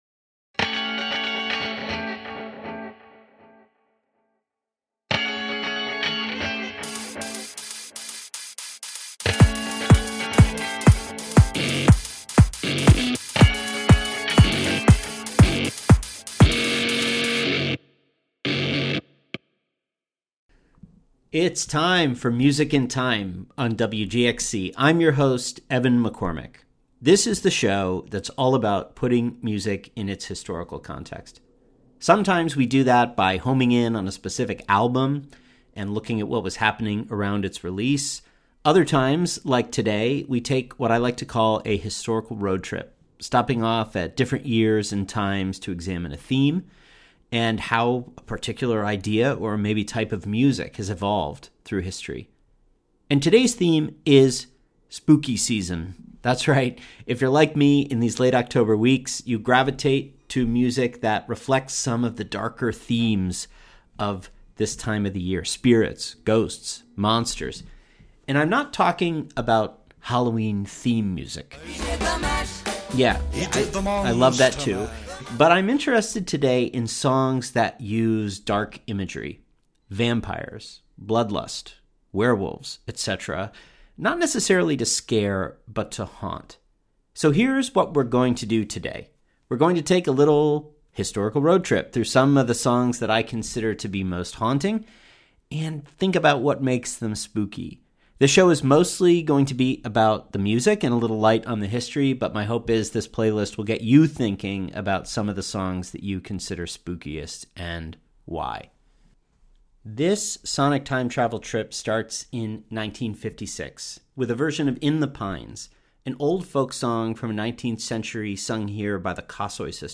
With stops highlighting eerie and macabre tunes from different eras — from folk to punk to alternative — listeners are prompted to think about different ways that dark forces lurk in the music we listen to during the Halloween season. With a playlist featuring lonely scarecrows, punk zombies, lovelorn werewolves, and sad ghosts, it's an episode...to die for!